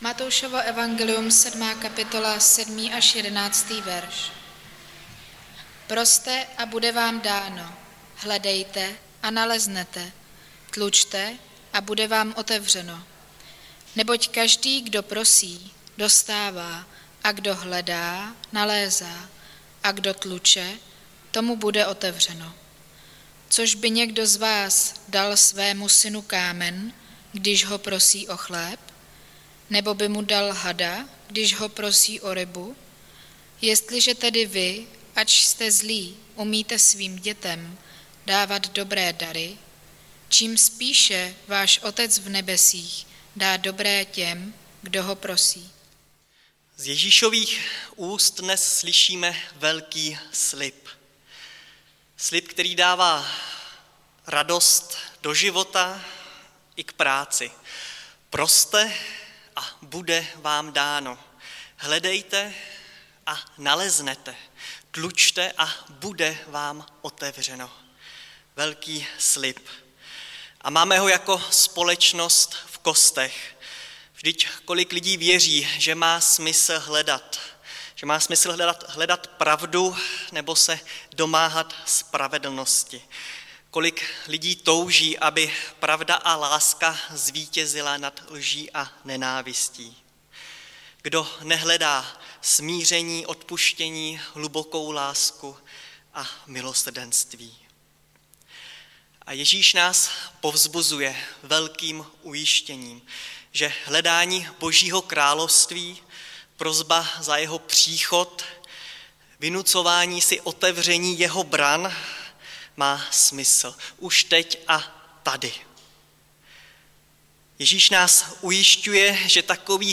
Nedělní kázání – 6.2.2022 Proste, hledejte, tlučte